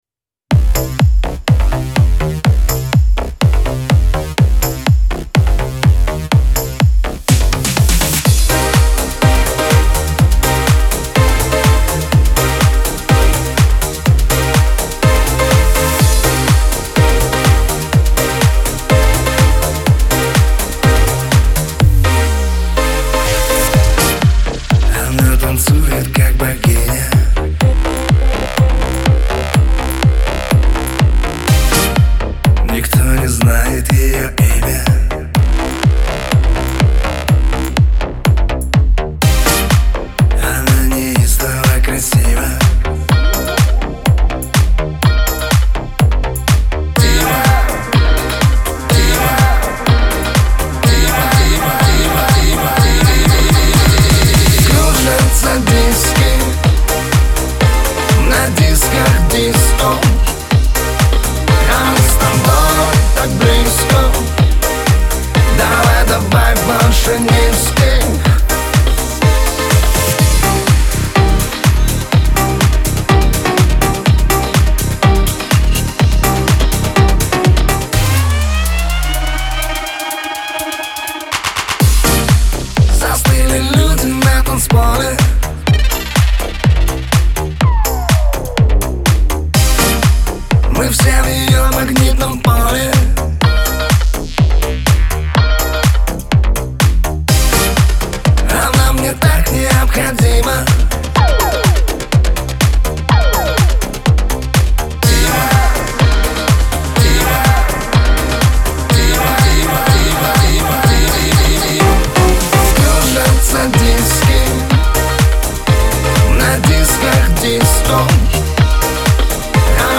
pop
диско